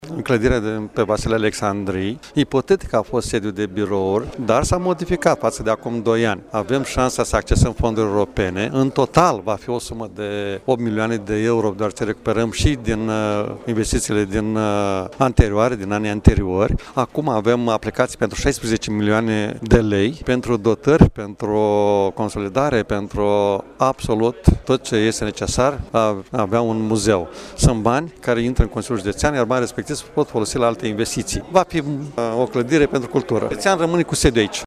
Într-un interviu pentru  RadioHit, Președintele Consiliului Județean Maricel Popa susține că avem șansa să accesăm fonduri europene pentru dotări și consolidarea clădirii.